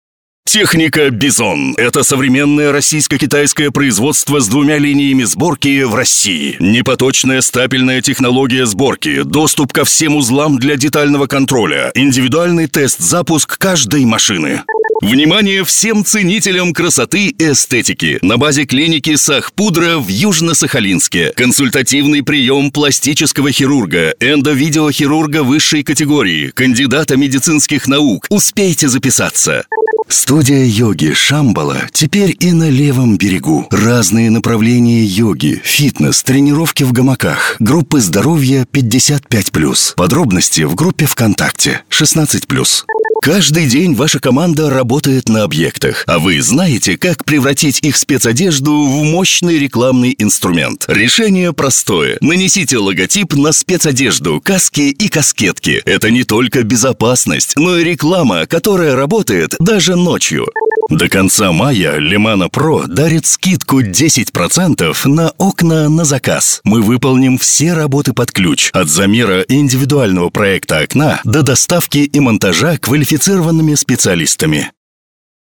Баритон, низкий, солидный.